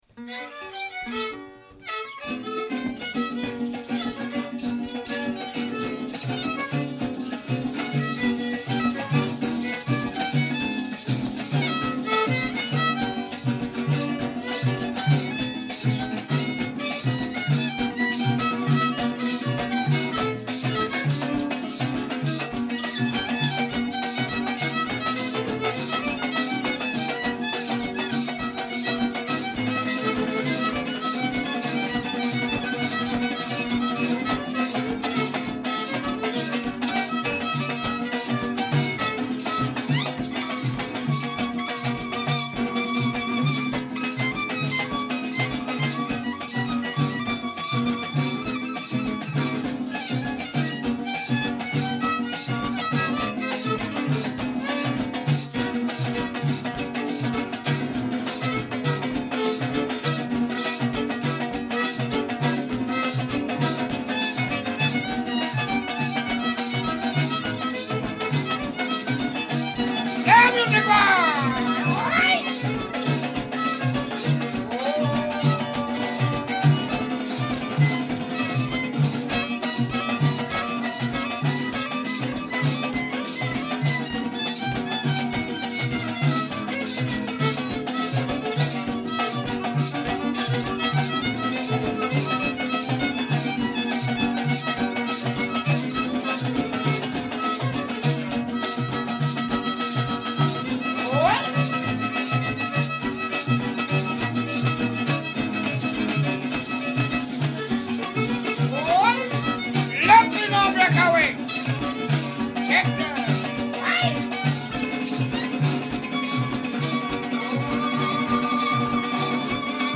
Inspelningarna är gjorda på fältet
Parang - paseo (breakaway)  Dansmusik.